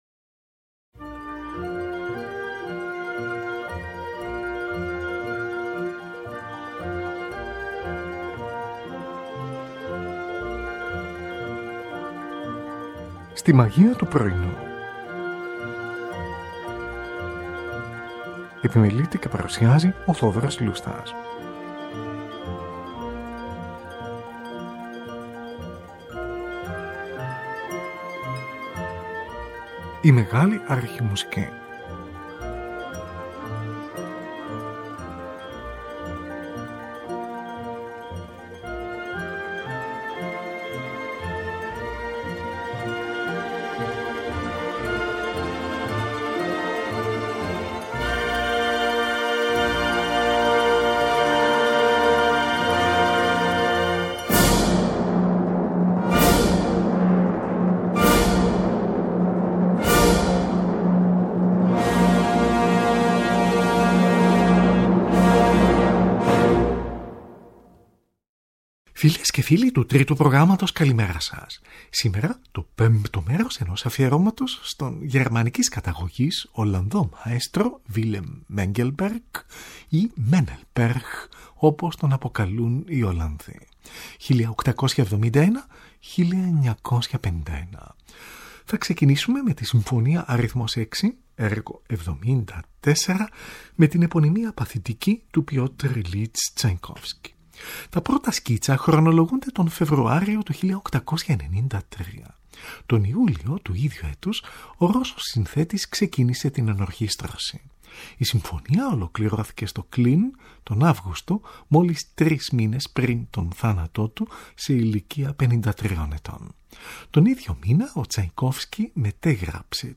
Συμφωνία Αρ. 6
για έγχορδα και basso continuo